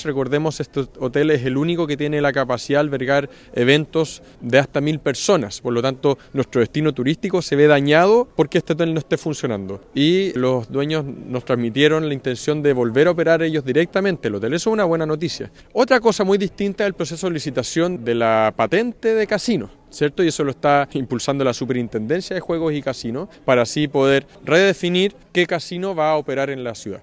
El alcalde Tomás Gárate se refirió a posibles soluciones que se han barajado en estos casos.